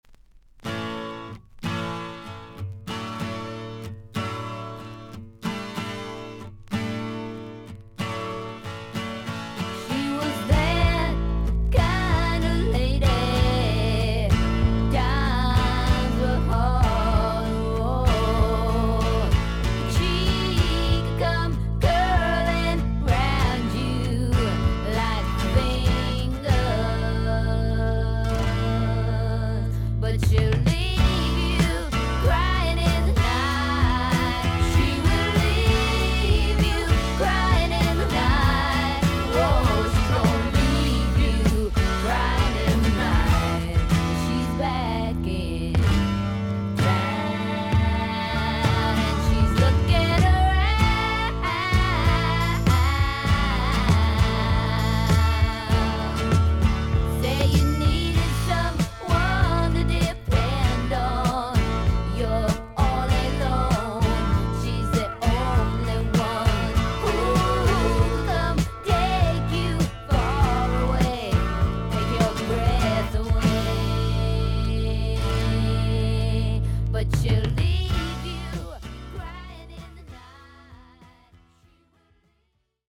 音のグレードはVG+〜VG++:少々軽いパチノイズの箇所あり。少々サーフィス・ノイズあり。クリアな音です。